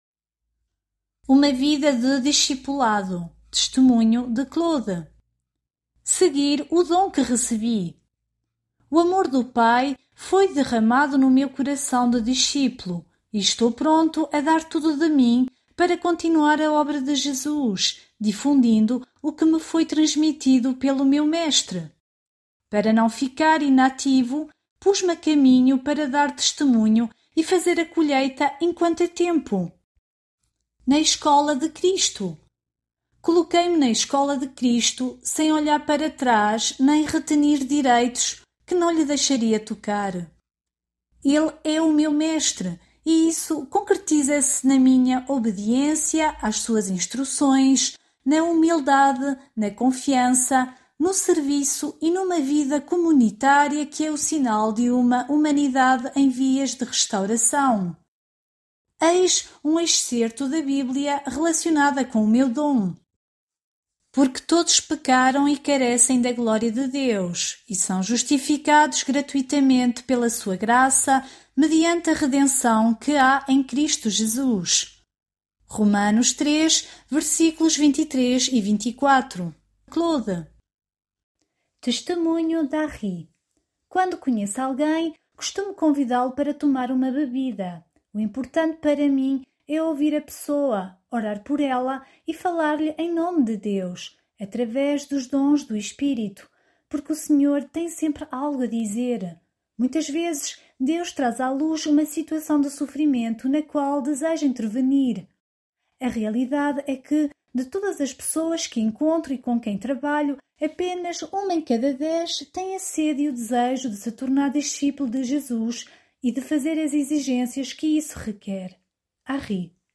Áudios, Testemunhos